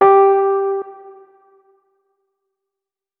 46265b6fcc Divergent / mods / Hideout Furniture / gamedata / sounds / interface / keyboard / electric_piano / notes-43.ogg 35 KiB (Stored with Git LFS) Raw History